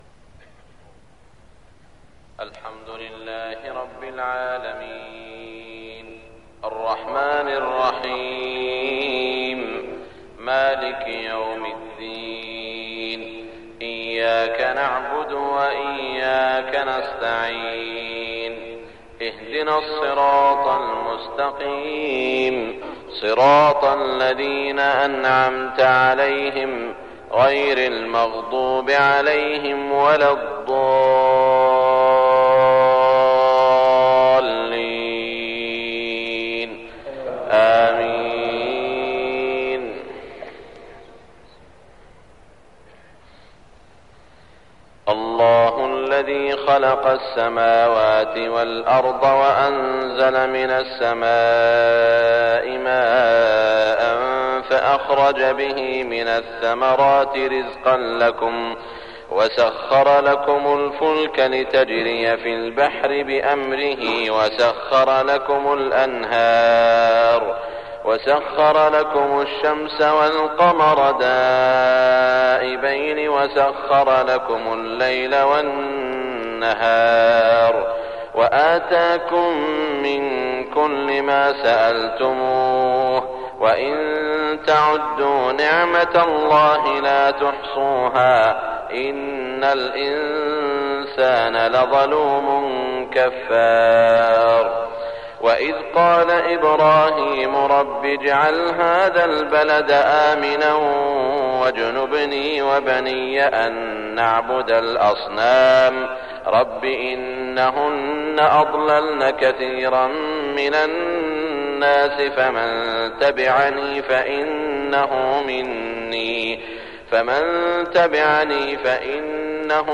صلاة الفجر 1418هـ آخر سورة إبراهيم > 1418 🕋 > الفروض - تلاوات الحرمين